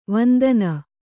♪ vandana